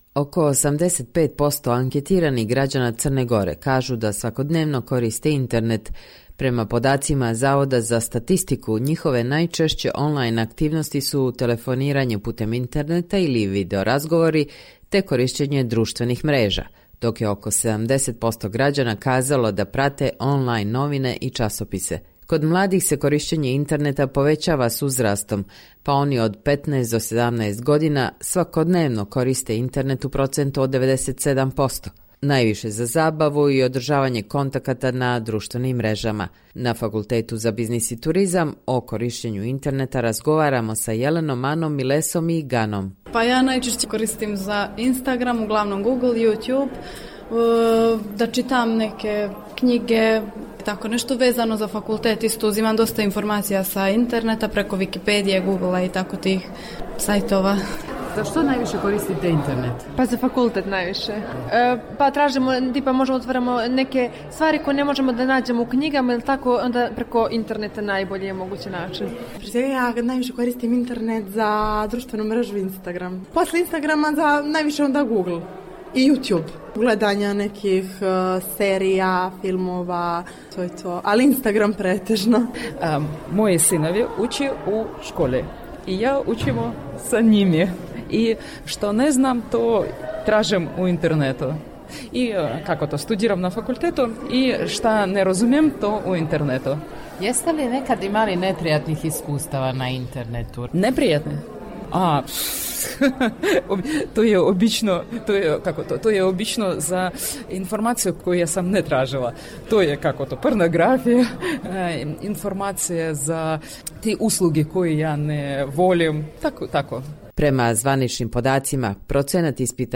Istraživanja pokazuju da najveći procenat mladih u Crnoj Gori koristi internet za zabavu i kontakte na društvenim mrežama, dok je manji broj onih koji koriste internet i kao pomoć u procesu obrazovanja. Naši sagovornici, studenti sa Fakulteta za biznis i turizam iz Budve, kažu da je lažno reklamiranje proizvoda na internetu oblast u kojoj su imali negativnih iskustava.